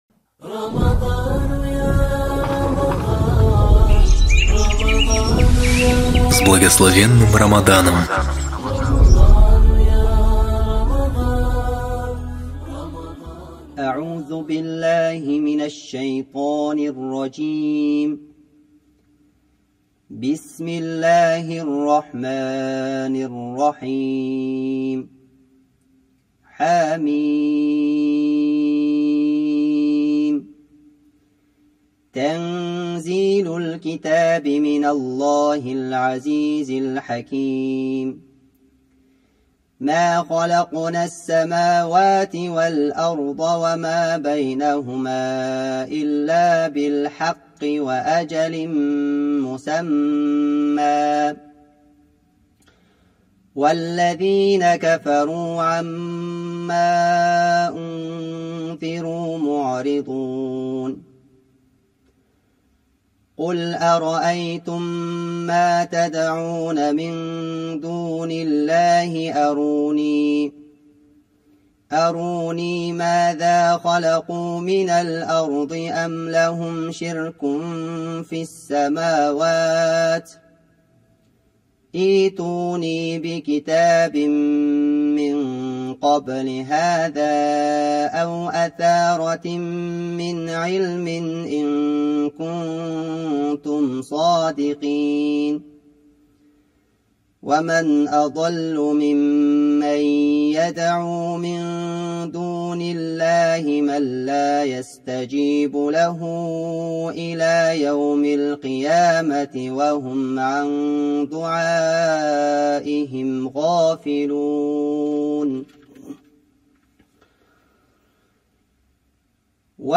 Хатм Корана читаемый в Центральной мечети г.Алматы в период Священного месяца Рамадан.